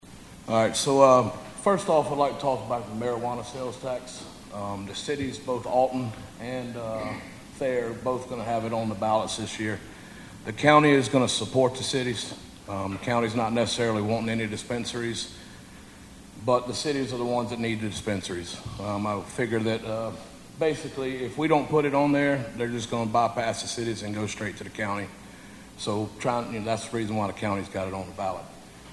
A Townhall Meeting was held Tuesday at Thayer High School
Several Ballot initatives were discussed, and County Commissioner Jake Parker talked about a marijuana tax